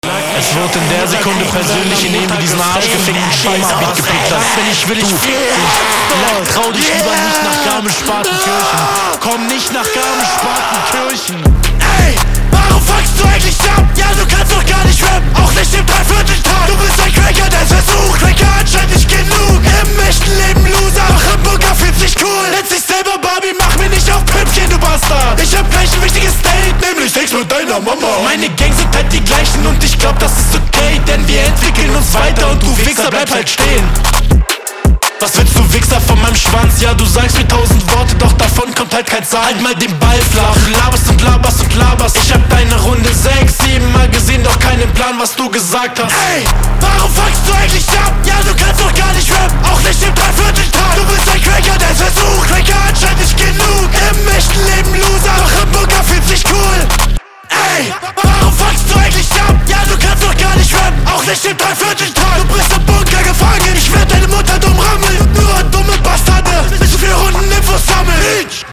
Das ist eindeutig mehr dein Style.